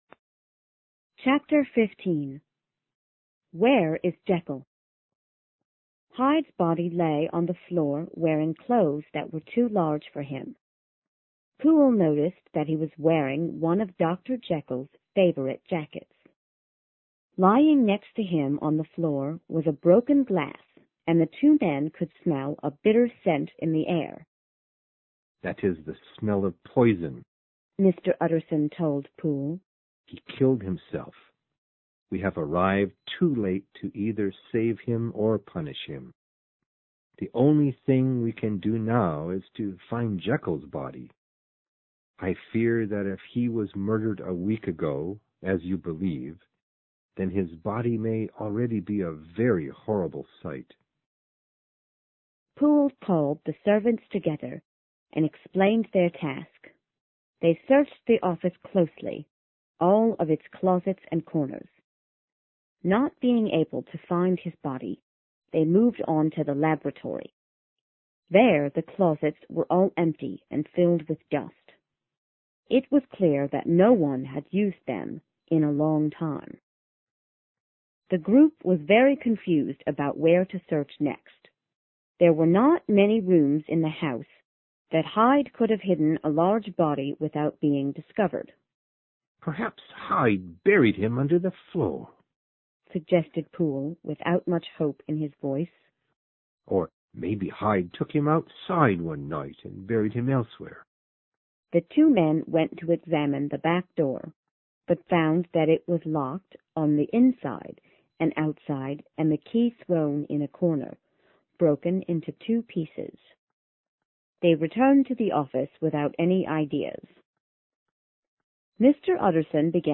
在线英语听力室有声名著之化身博士15的听力文件下载,有声名著化身博士-在线英语听力室